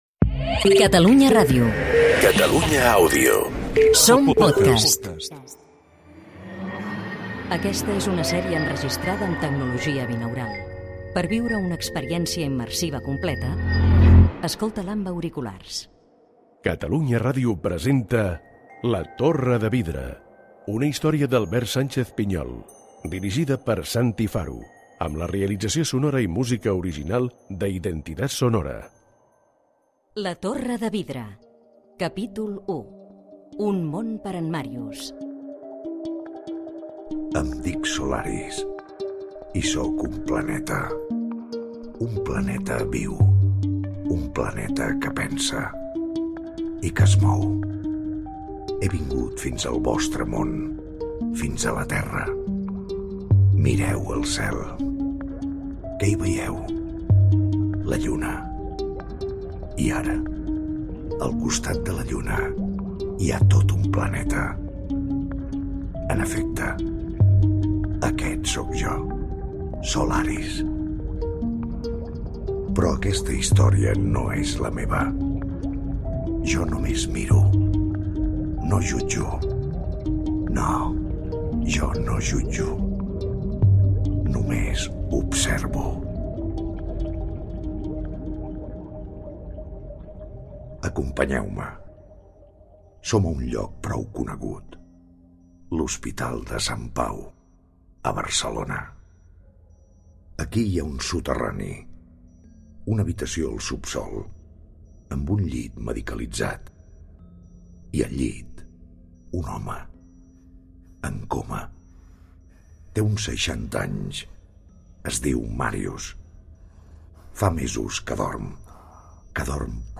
Identificació de Catalunya Àudio. Careta del programa. Capítol 1 "Un món per en Màrius". Gènere radiofònic Ficció
Primera sèrie de ficció en àudio immersiu, distribuïda en forma de pòdcast, que estrena Catalunya Ràdio.